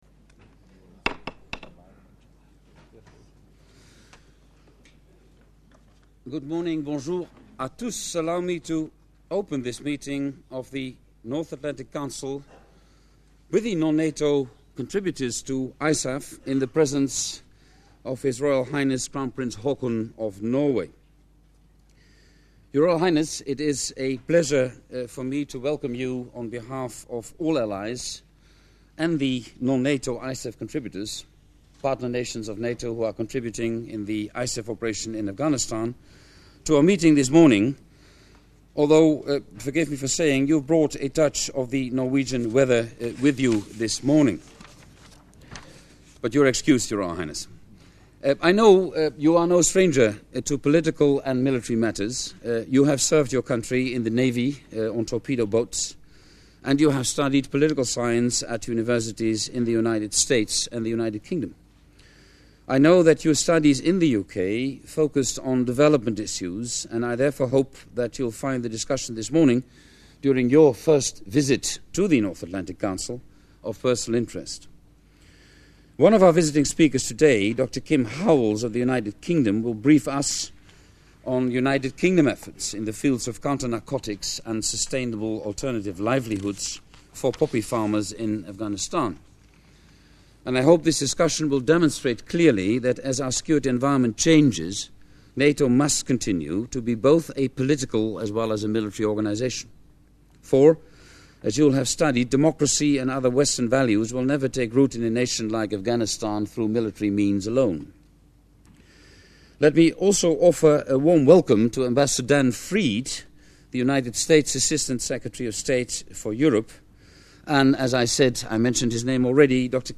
Audio Opening remarks by NATO Secretary General, Jaap de Hoop Scheffer and H.R.H Crown Prince Haakon of Norway, opens new window